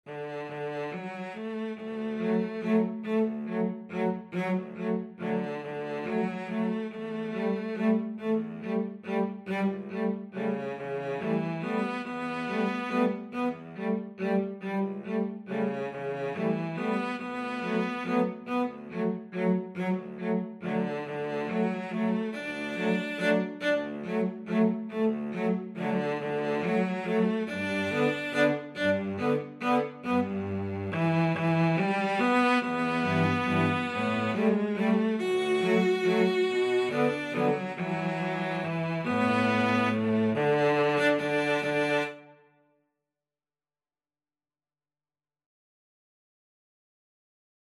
Free Sheet music for Cello Duet
D major (Sounding Pitch) (View more D major Music for Cello Duet )
3/4 (View more 3/4 Music)
=140 Slow one in a bar
Classical (View more Classical Cello Duet Music)